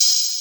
Gamer World Open Hat 3.wav